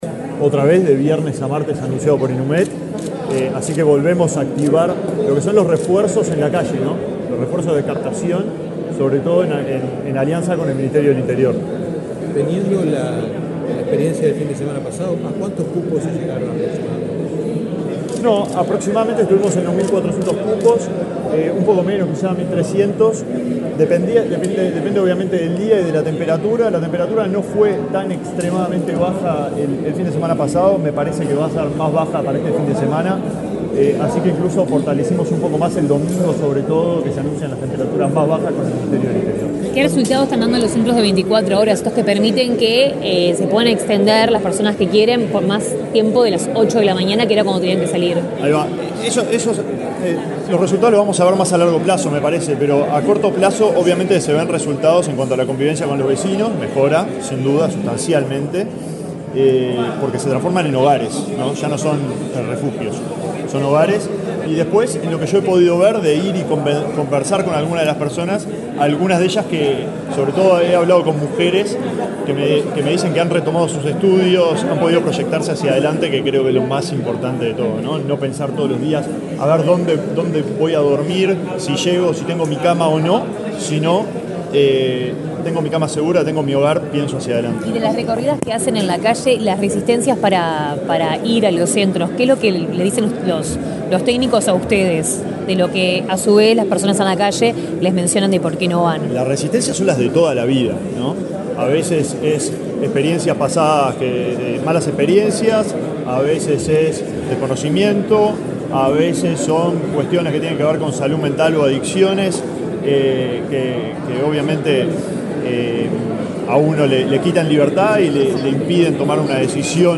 Declaraciones del ministro de Desarrollo Social, Alejandro Sciarra
Declaraciones del ministro de Desarrollo Social, Alejandro Sciarra 24/05/2024 Compartir Facebook X Copiar enlace WhatsApp LinkedIn El ministro de Desarrollo Social, Alejandro Sciarra, dialogó con la prensa, este viernes 24 en la Torre Ejecutiva, acerca de un nuevo operativo para este fin de semana, en el marco del Plan Invierno, dado el anuncio de una ola de frío.